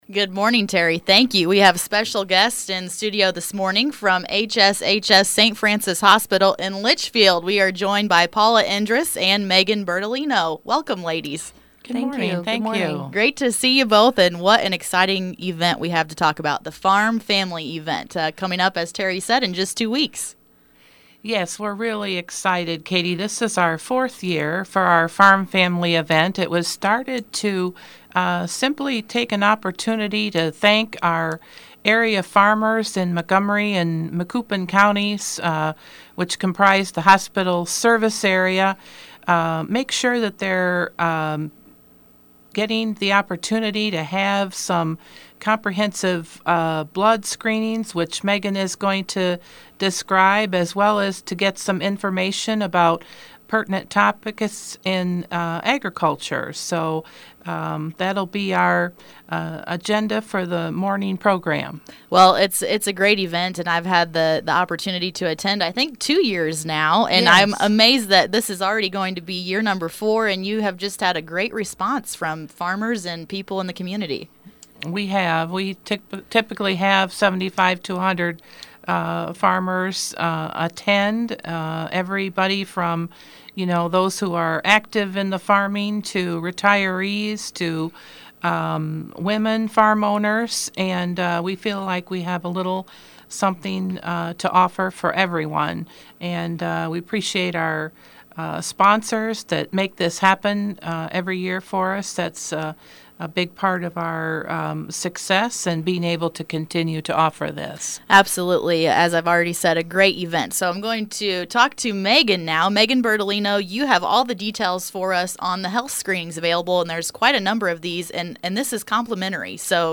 Podcasts - Interviews & Specials